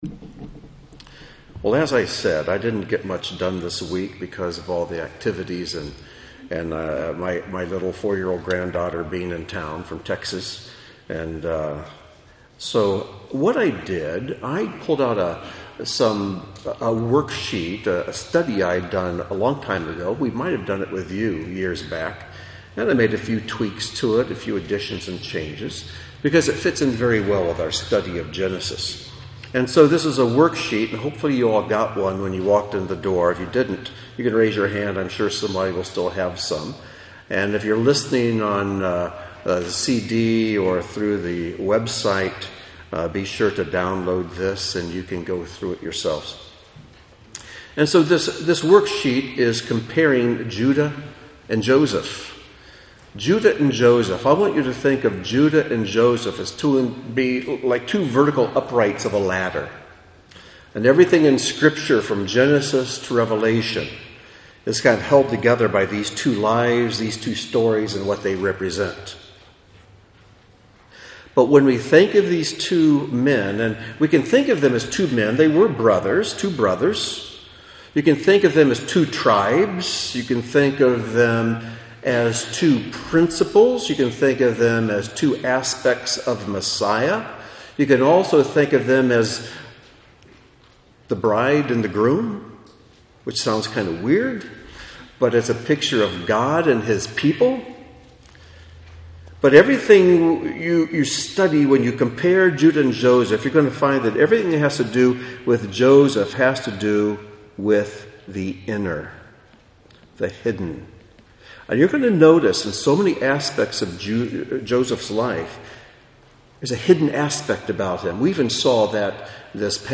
In this Torah Project teaching, we closely compare and contrast the two brothers Judah and Joseph, finding in them pictures of the “outer” and the “inner” person, the seen and the unseen.